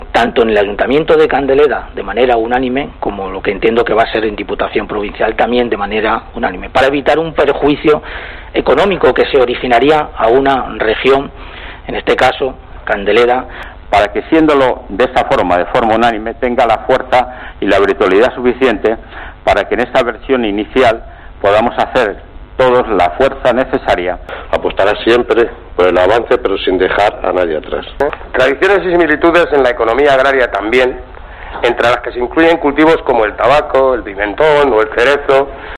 -sonido-del-pleno-Diputación Ávila--Candeleda